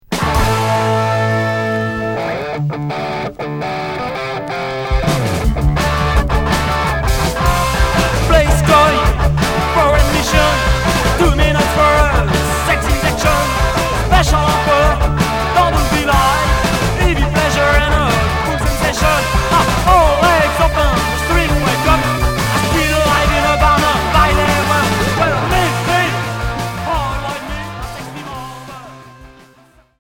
Punk rock Unique 45t